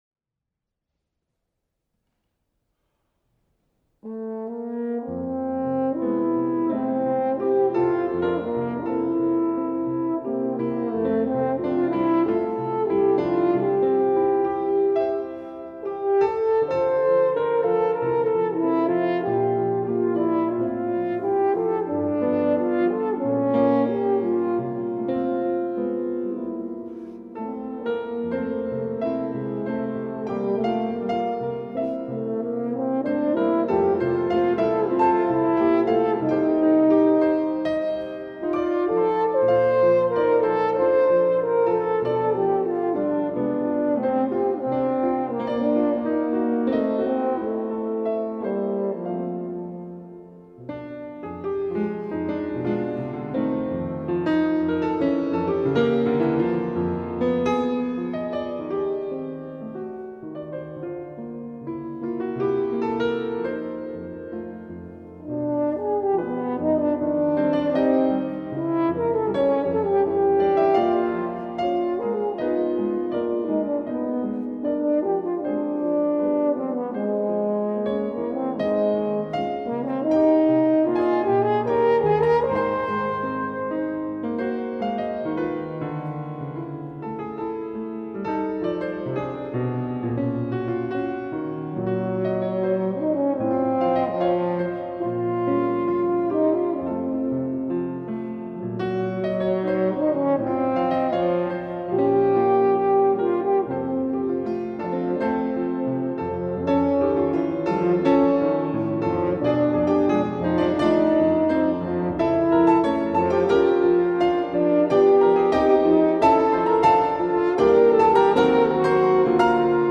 23 NOVEMBRE 2018 – 18° Concerto di Musica Classica
Leone Sinigaglia: Lied e Humoresque op.28 per corno e pianoforte
Come da tradizione l’Associazione “Ed allora sarà sempre festa per te” ha offerto alla cittadinanza il “Concerto di Natale”, nella prestigiosa location della SALA DEI GIGANTI a Palazzo Liviano dell’Università degli Studi di Padova.